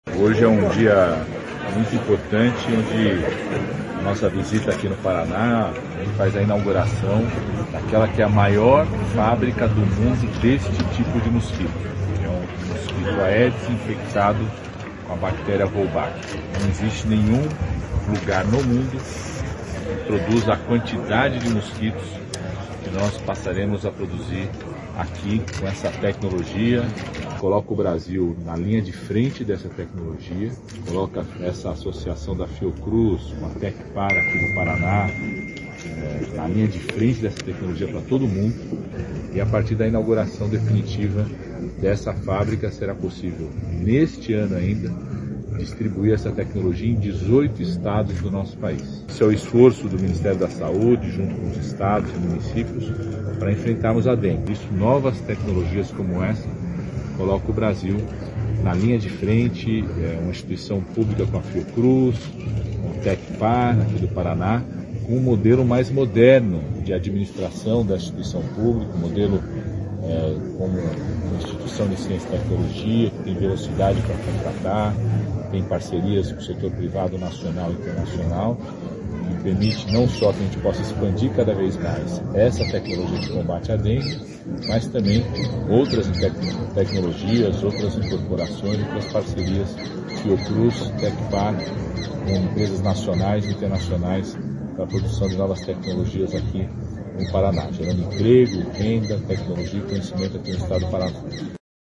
Sonora do ministro da Saúde, Alexandre Padilha, sobre a inauguração da maior biofábrica de mosquitos do mundo de combate à dengue